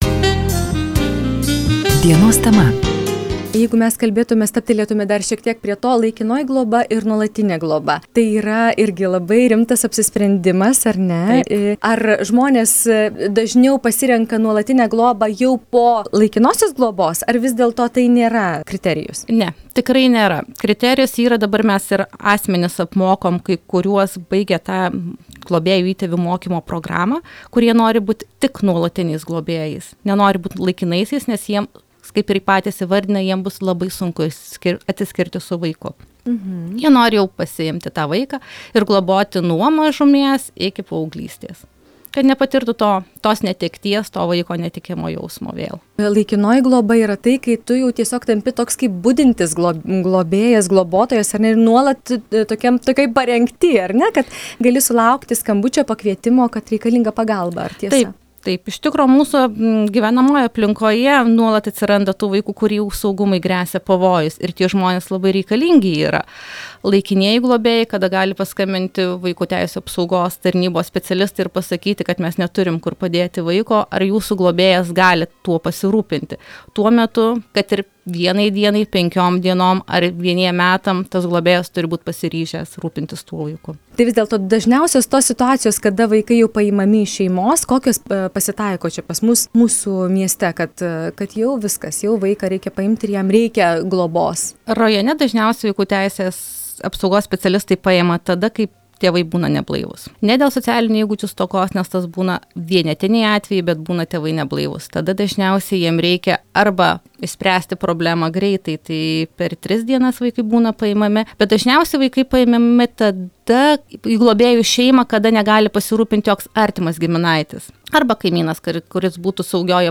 Šiandien FM99 studijoje viešėjo Alytaus rajono savivaldybės mero pavaduotoja Dalia Kitavičienė